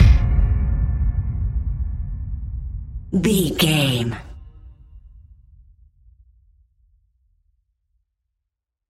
Sound Effects
Aeolian/Minor
Fast
scary
tension
ominous
dark
driving
synthesiser
drums
drum machine